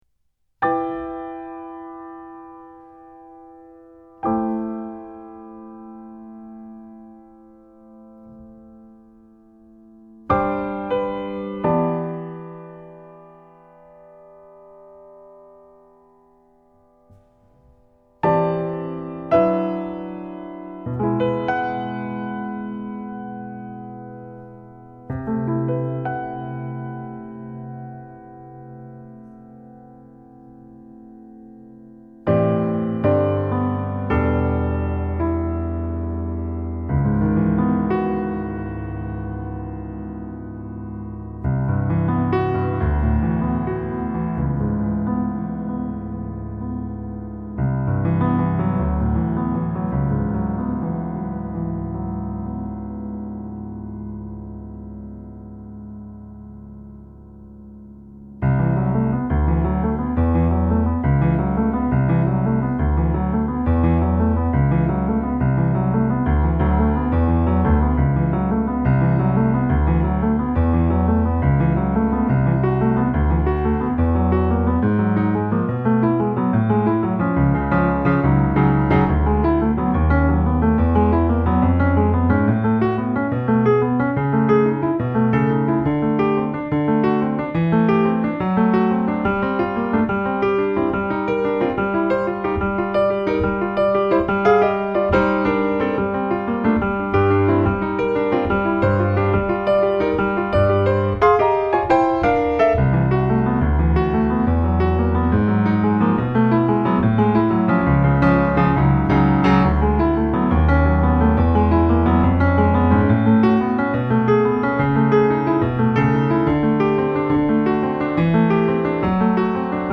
Klavier & Kompositionen
Stimme
Posaune
Schlagzeug